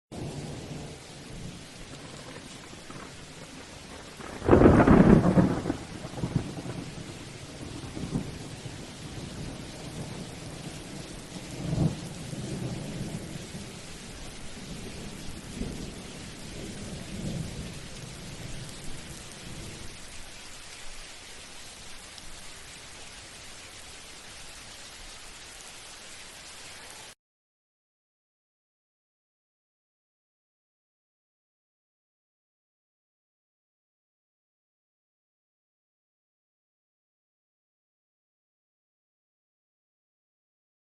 Truenos-Rayo-tormenta-lluvia-Efecto-de-Sonido-Lightning-storm-rain-Sound-Effect-50k.mp3
KGlxJ1HHVcO_Truenos-Rayo-tormenta-lluvia-Efecto-de-Sonido-Lightning-storm-rain-Sound-Effect-50k.mp3